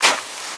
High Quality Footsteps / Sand
STEPS Sand, Walk 14.wav